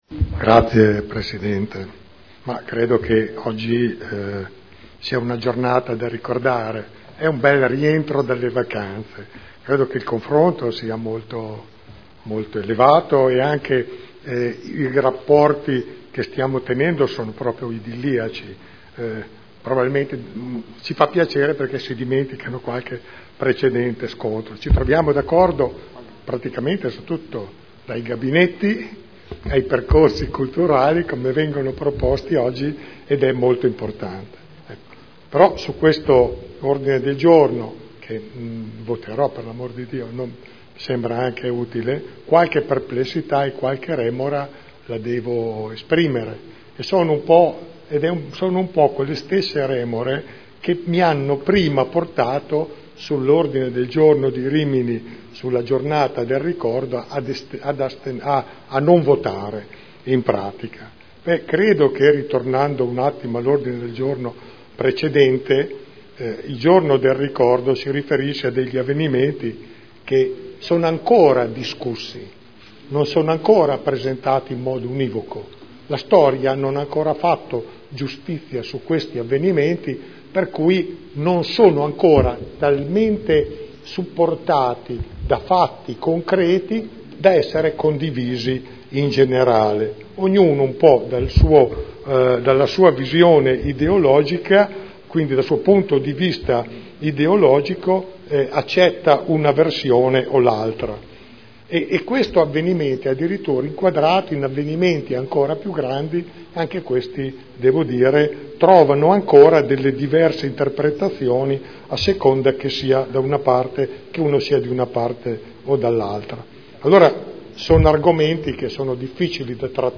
Giancarlo Pellacani — Sito Audio Consiglio Comunale